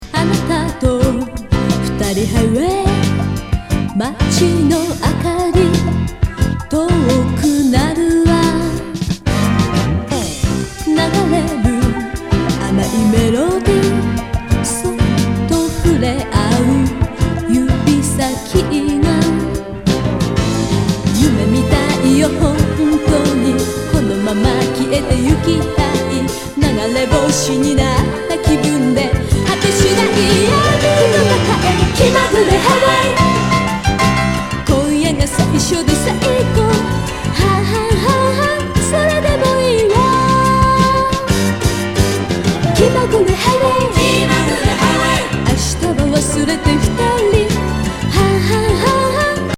ヨーロピアン・テクノ・ポップ!